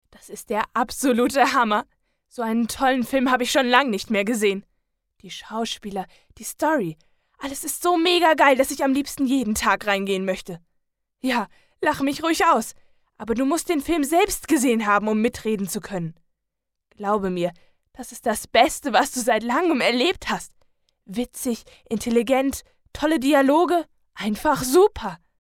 Sprecher deutsch
Kein Dialekt
Sprechprobe: eLearning (Muttersprache):